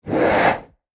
Torch, Swish | Sneak On The Lot
Close Handheld Torch Whoosh, X6